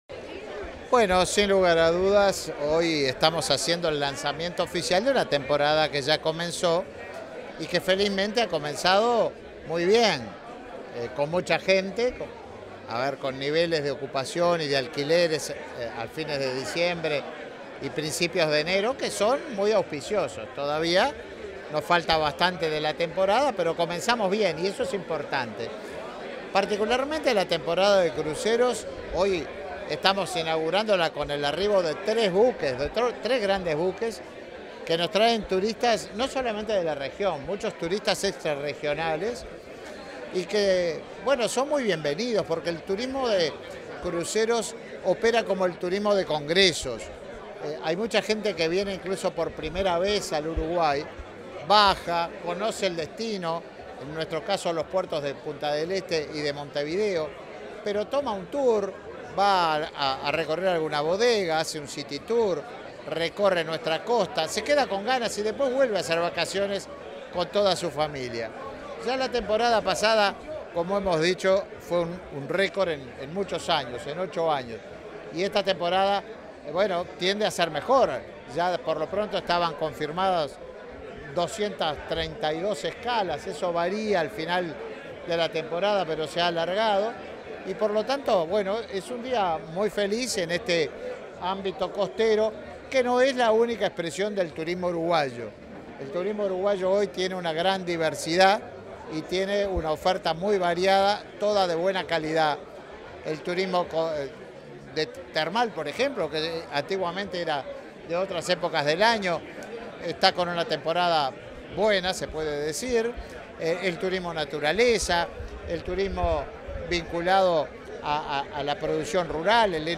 Declaraciones del ministro de Turismo, Tabaré Viera
Tras el lanzamiento de la temporada de cruceros 2023-2024, el ministro de Turismo, Tabaré Viera, dialogó con la prensa.